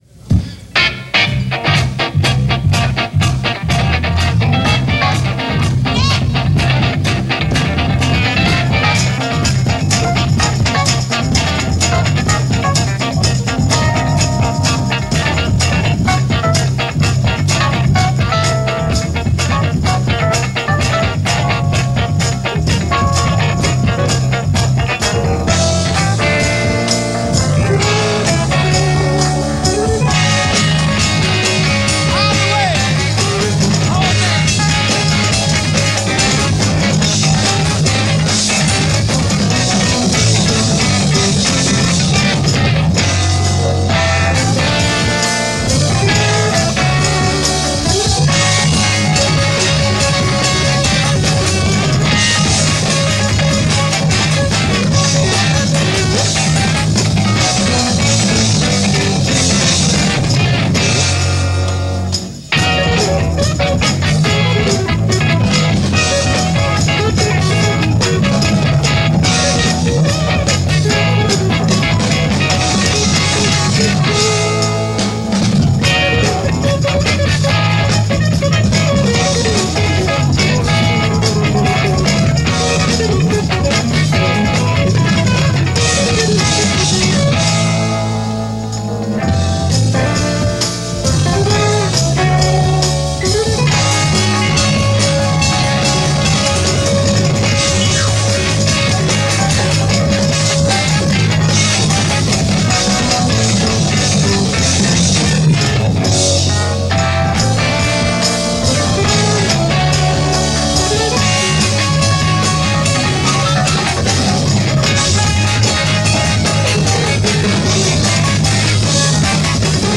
R&B and Soul